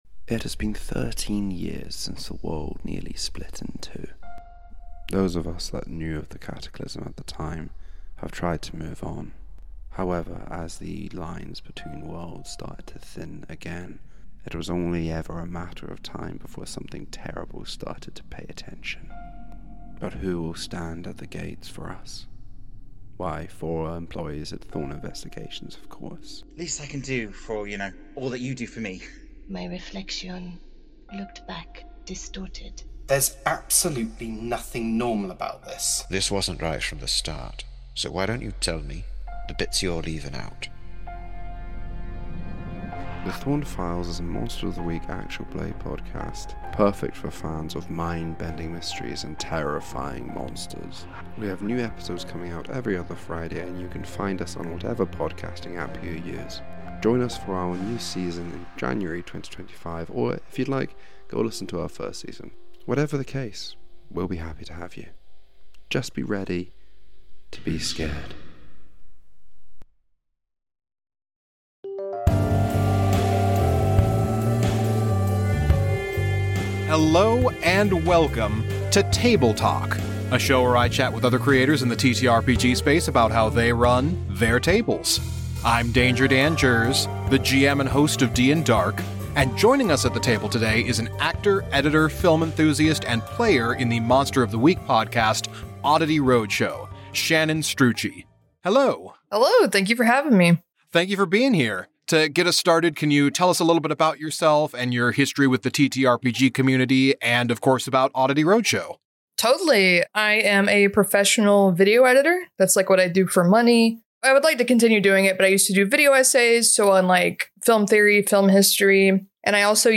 A comedy/horror D&D Realplay Podcast starring the iconic monsters of the Golden Age of Horror